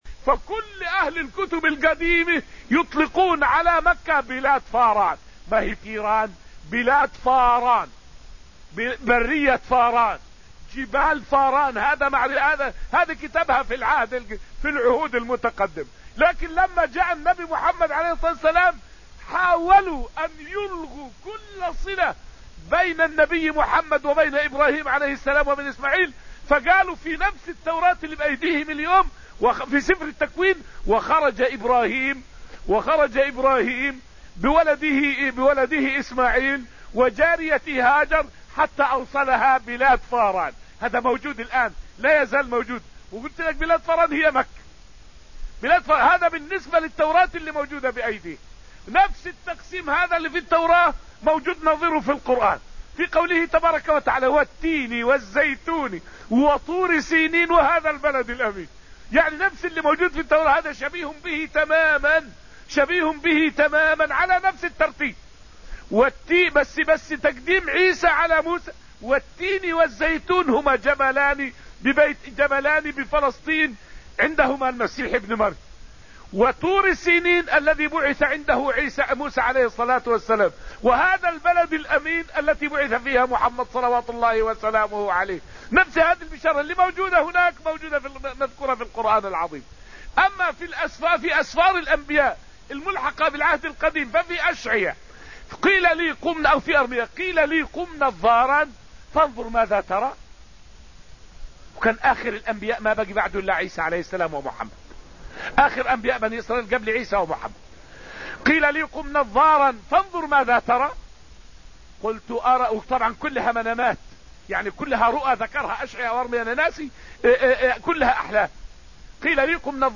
فائدة من الدرس السابع عشر من دروس تفسير سورة الحديد والتي ألقيت في المسجد النبوي الشريف حول بشارات الكتب القديمة ببعث النبي صلى الله عليه وسلم.